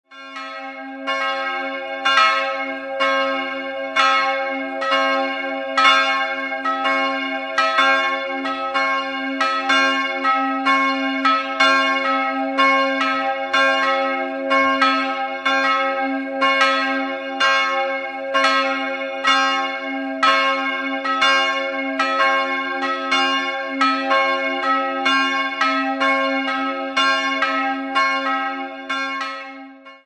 2-stimmiges Kleine-Terz-Geläute: cis''-e''